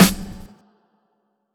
Snares
REGDS_SNR.wav